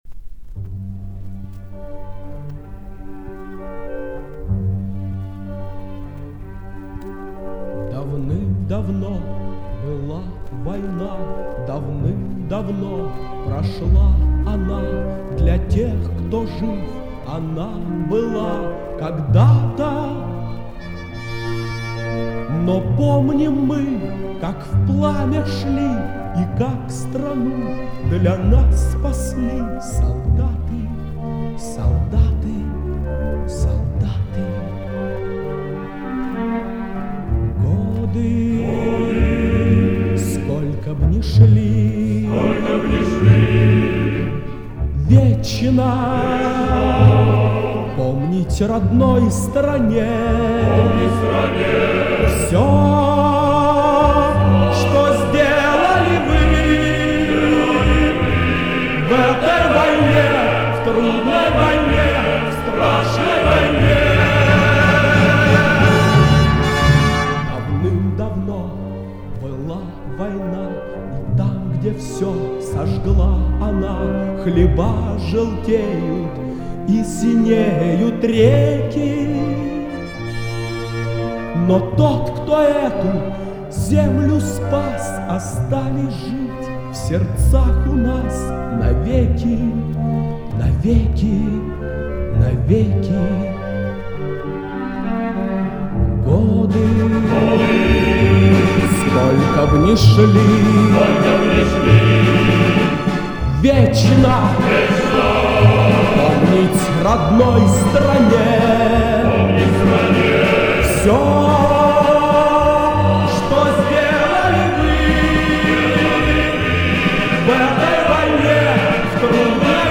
А вот с пластинки